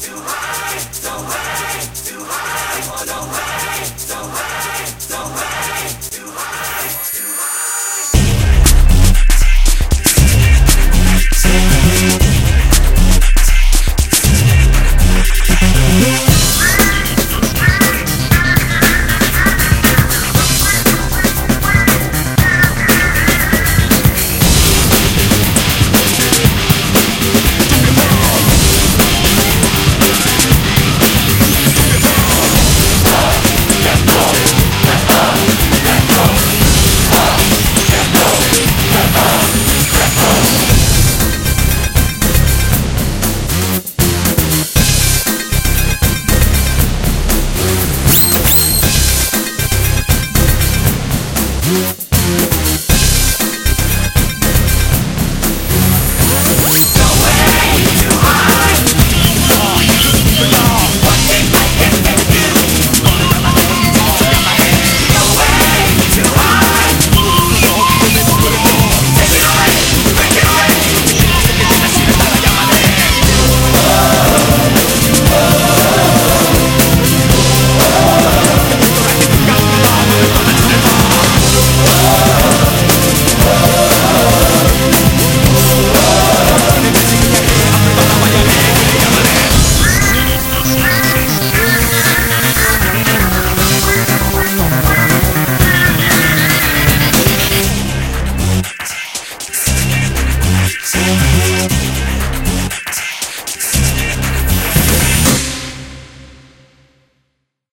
BPM118
Audio QualityPerfect (High Quality)